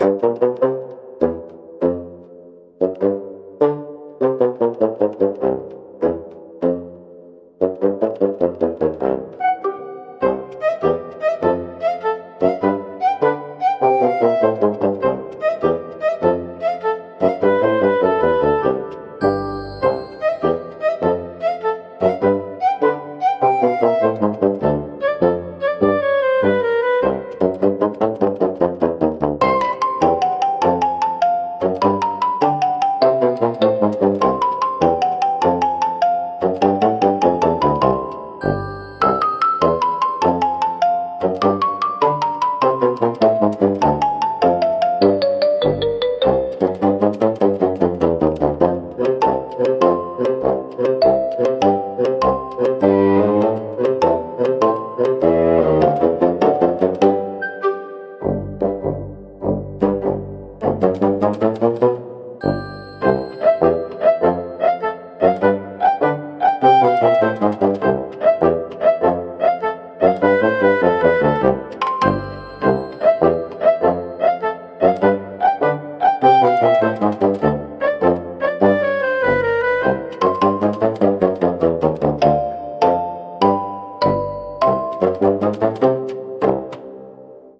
おとぼけは、バスーンとシロフォンを主体としたコミカルでドタバタした音楽ジャンルです。